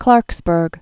(klärksbûrg)